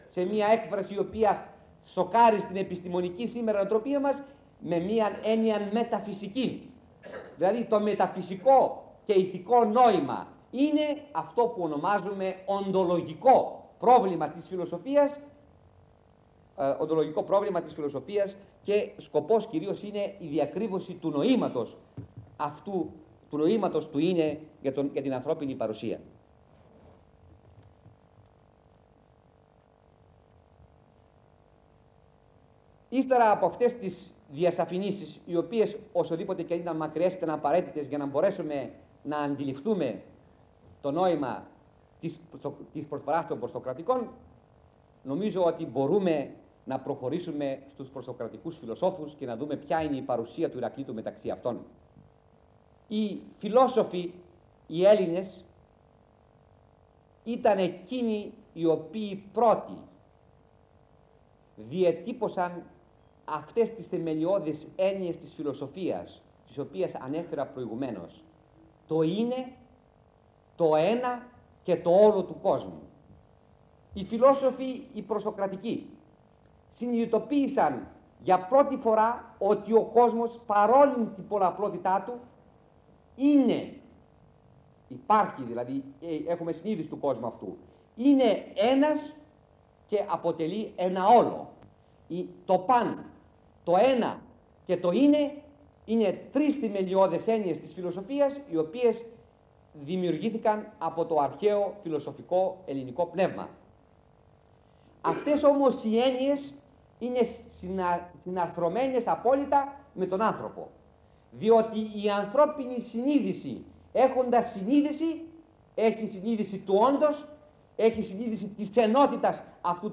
Λέξεις-Κλειδιά: κύκλος μαθημάτων; ηράκλειτος